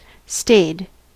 stayed: Wikimedia Commons US English Pronunciations
En-us-stayed.WAV